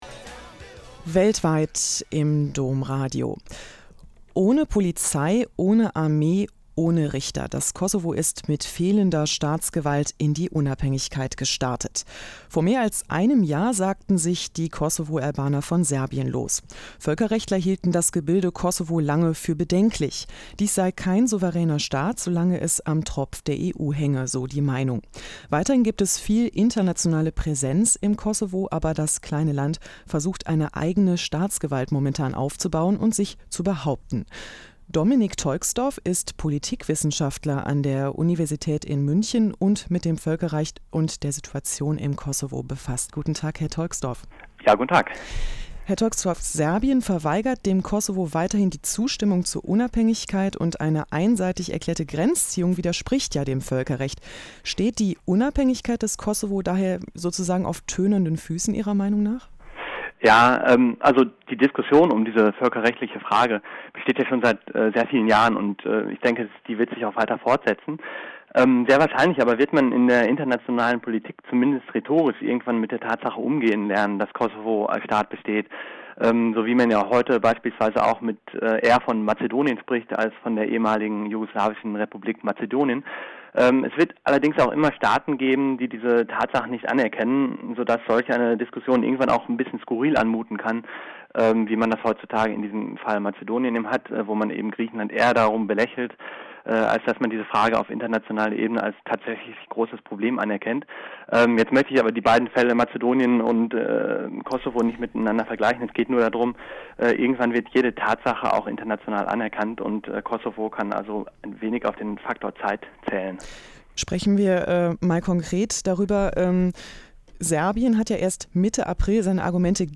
mp3-Audio des Interviews